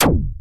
lasgun.ogg